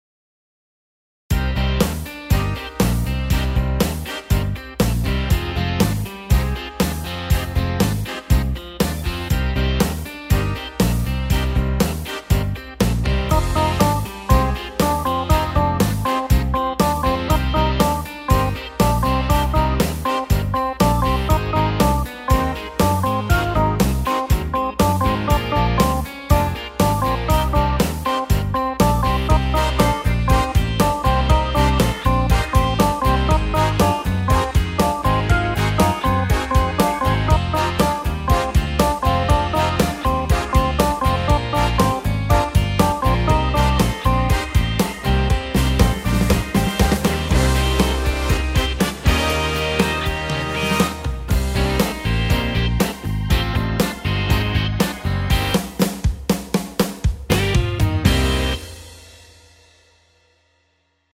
Cooler Sound.